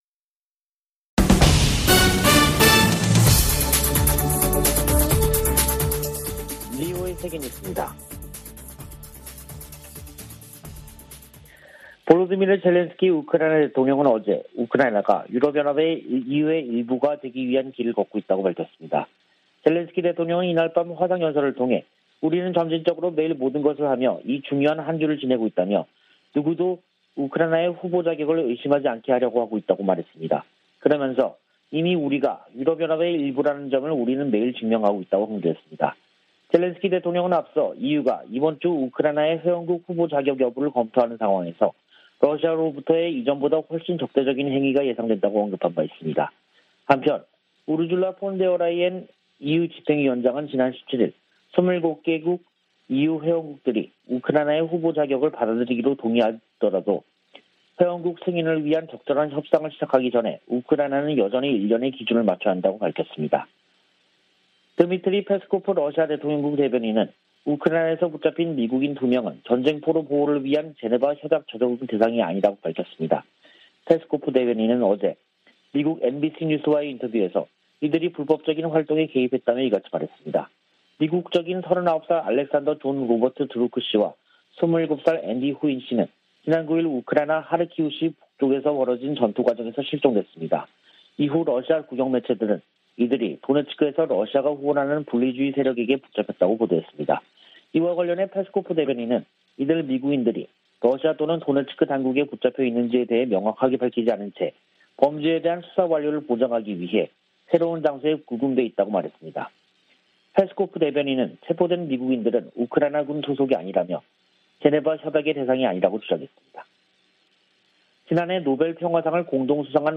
VOA 한국어 간판 뉴스 프로그램 '뉴스 투데이', 2022년 6월 21일 3부 방송입니다. 한국이 21일 자체 개발 위성을 쏘아올리는데 성공해, 세계 7번째 실용급 위성 발사국이 됐습니다. 북한이 풍계리 핵실험장 4번 갱도에서 핵실험을 하기 위해서는 수개월 걸릴 것이라고 미국 핵 전문가가 전망했습니다. 북한이 과거 기관총과 박격포 탄약, 수류탄 등 약 4천만개의 탄약을 중동 국가에 판매하려던 정황이 확인됐습니다.